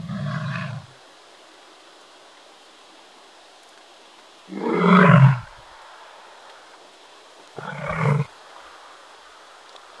Звук лошади, которая ржёт, и-го-го